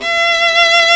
Index of /90_sSampleCDs/Roland - String Master Series/STR_Viola Solo/STR_Vla2 % marc